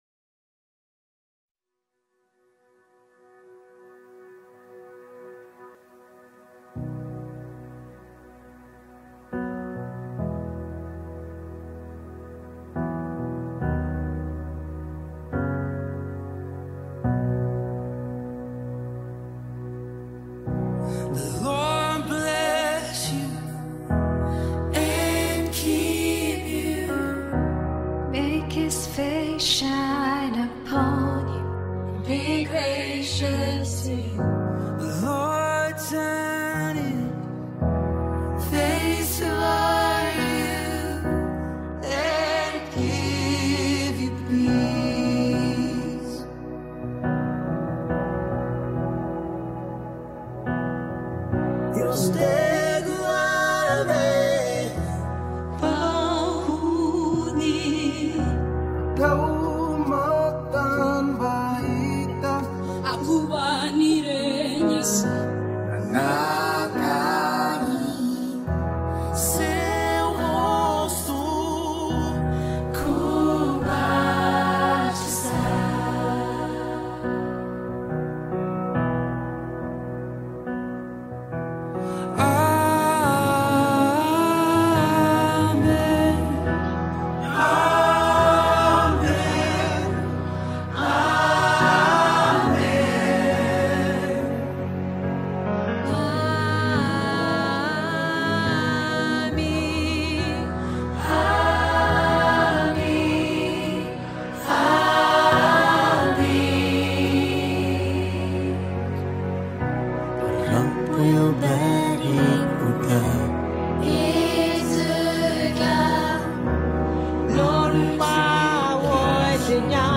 赞美诗
154国257种语言汇成一首诗歌